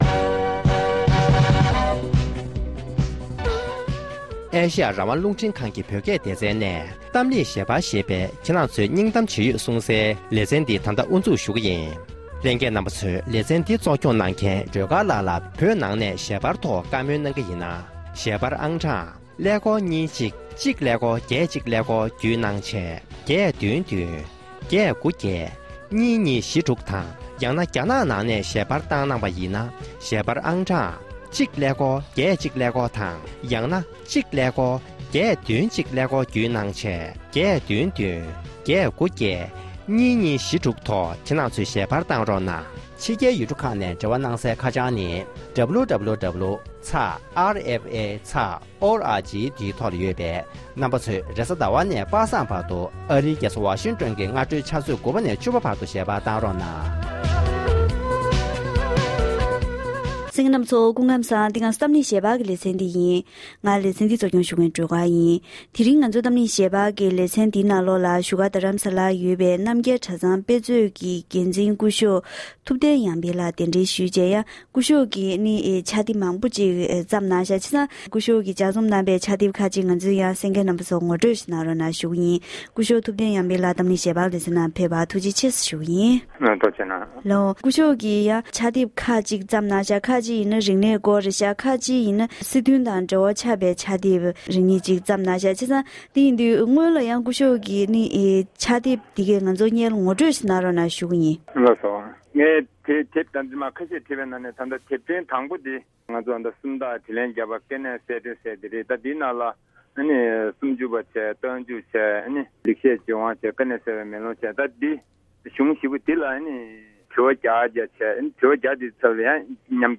ལྷན་གླེང་བ།